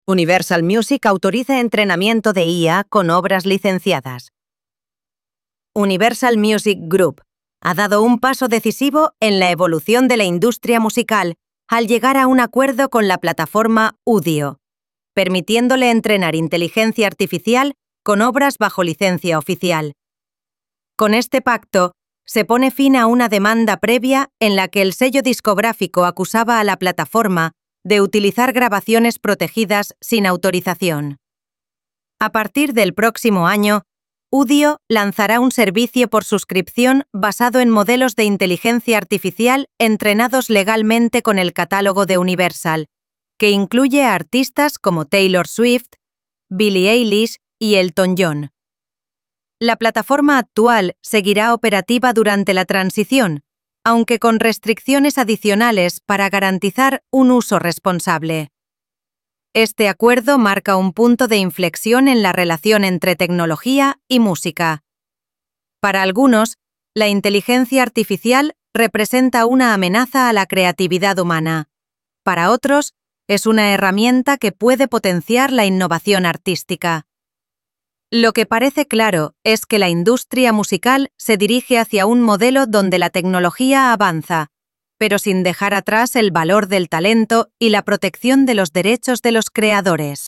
Narración informativa · MP3 · ~45–60 segundos